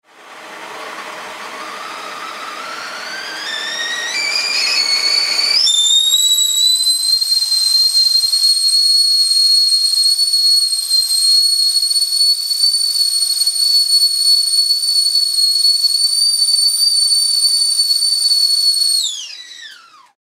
Звуки кипящего чайника
Свист и шипение